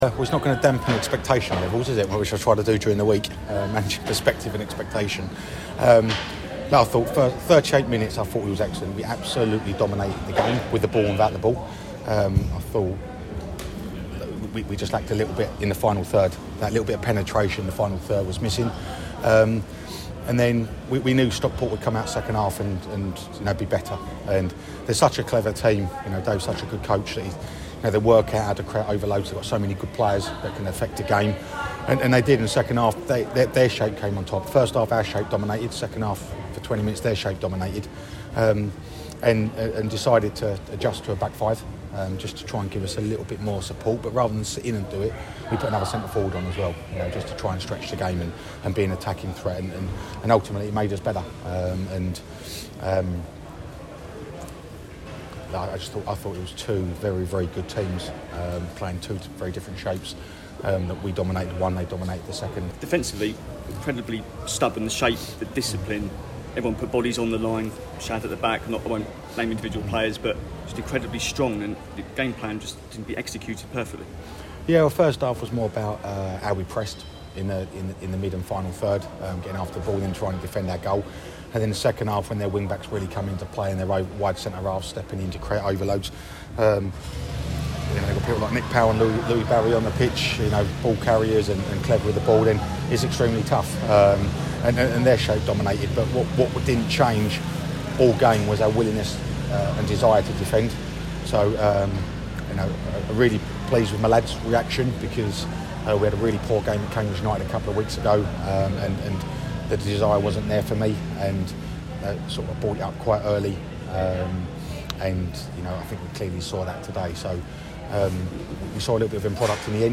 Listen: Gillingham manager Neil Harris reacts to their win over Stockport County on the opening day of the season - 06/08/2023